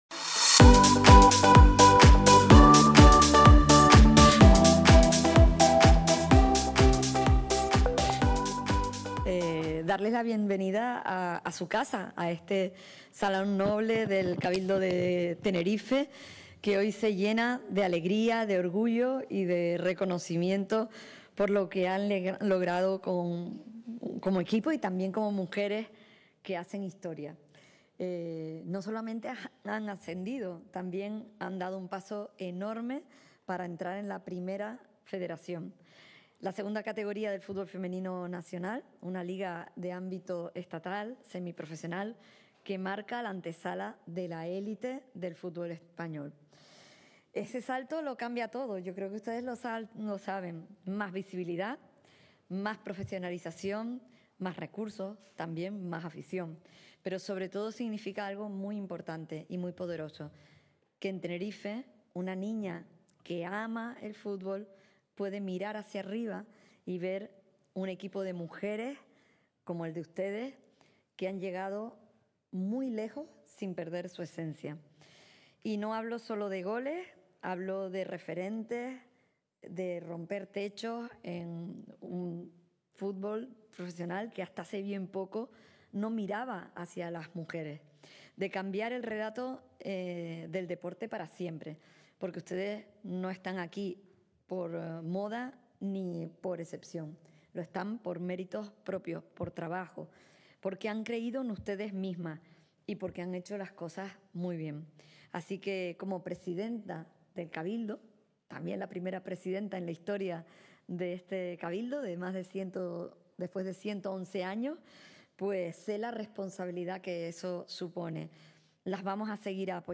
El Cabildo de Tenerife celebró hoy, lunes 12 de mayo, una recepción en el Salón Noble del Palacio Insular al Fundación CD Tenerife, en reconocimiento al equipo recientemente proclamado campeón del Grupo 2 de la Segunda Federación y ascendido a...